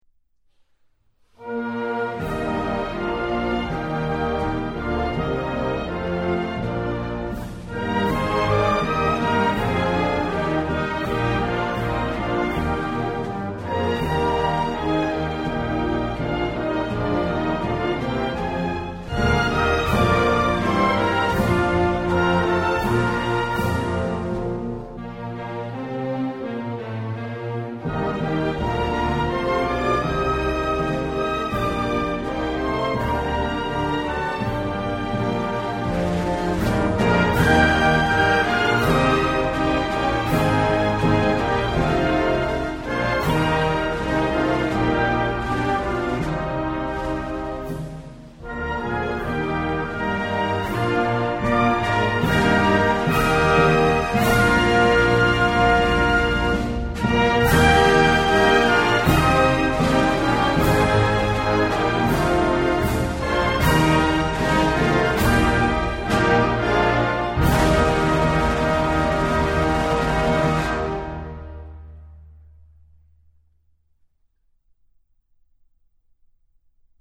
Hymn
hymn.mp3